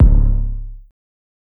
KICK_RESTCL.wav